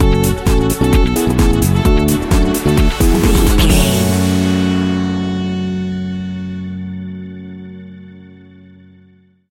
Groovy Funk Electro Stinger.
Aeolian/Minor
F#
uplifting
energetic
funky
saxophone
bass guitar
drums
synthesiser
electric organ
funky house
disco
upbeat